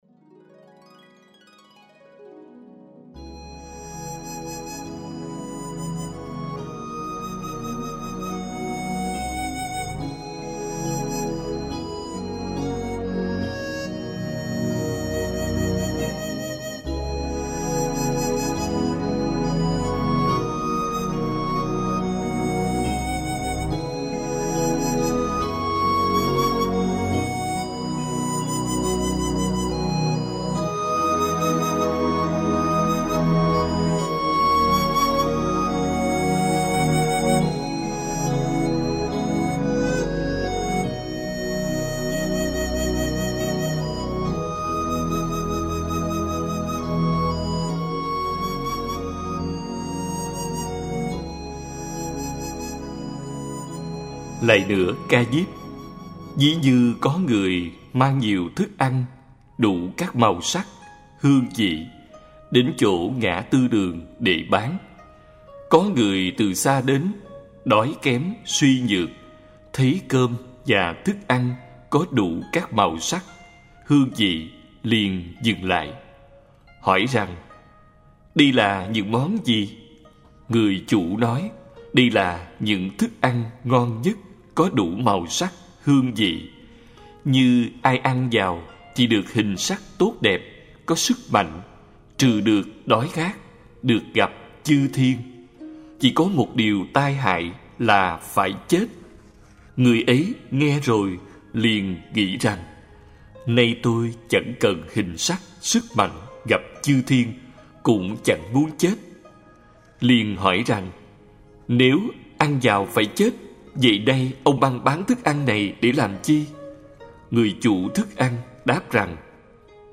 Kinh Tụng: Kinh Diệu Pháp Liên Hoa Khai Kinh Diệu Pháp Liên Hoa 1.